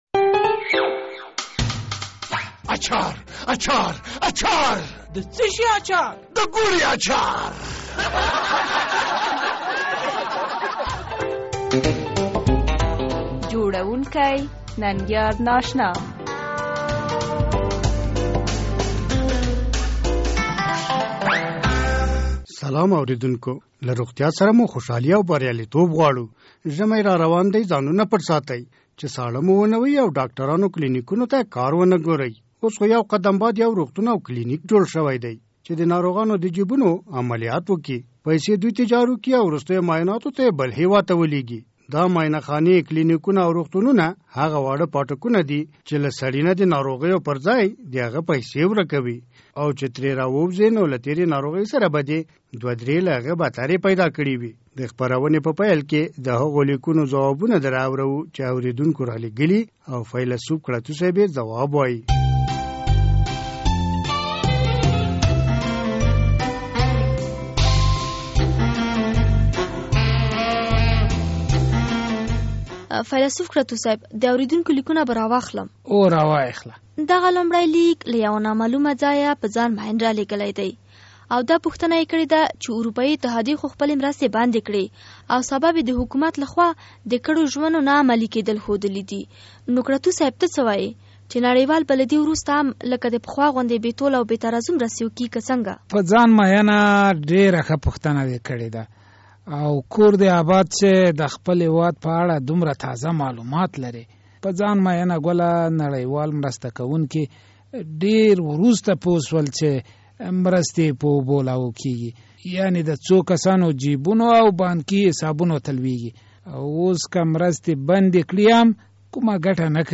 د ازادي راډیو د اوونۍ طنزي خپرونه د ګوړې اچار